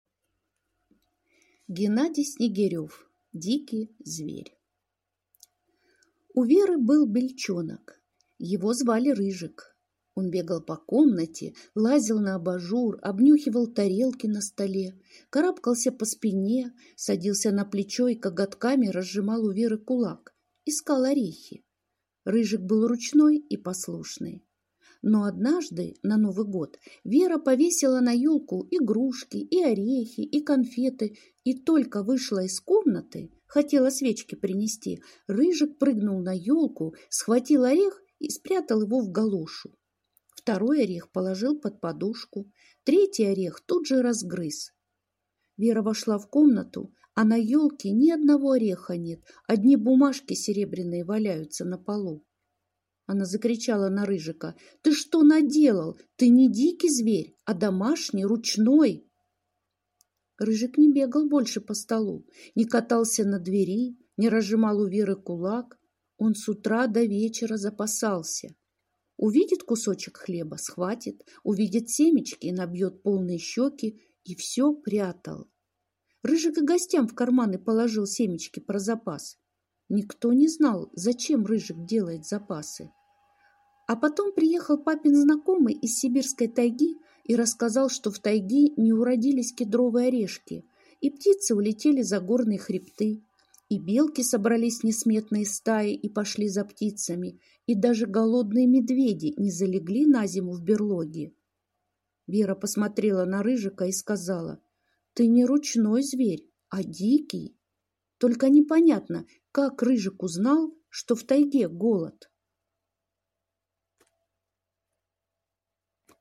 Аудиорассказ «Дикий зверь»